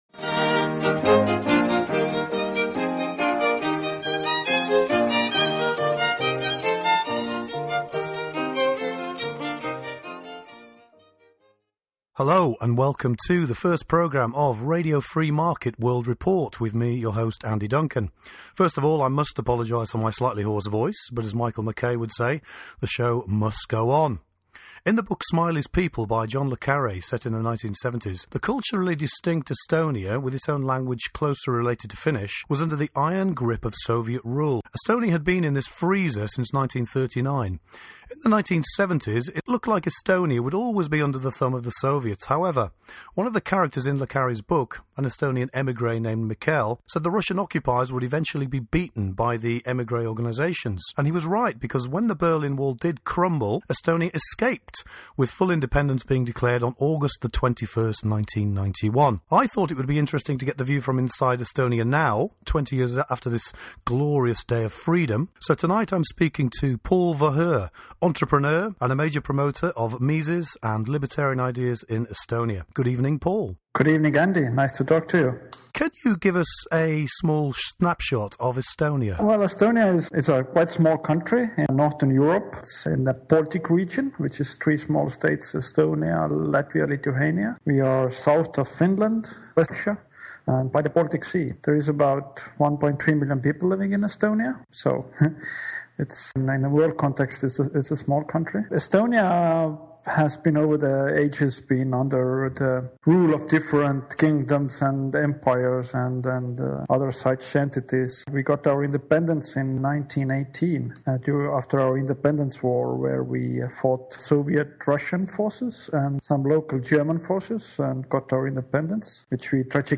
I will also interview the authors of recent books about their work, to help promote their ideas.